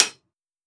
Blacksmith hitting hammer 6.wav